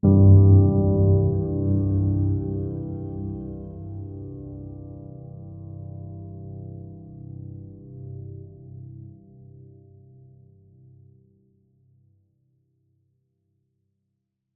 piano7.wav